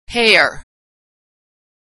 Homophones - Authentic American Pronunciation
same pronunciation